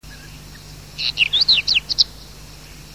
głosy innych białorzytek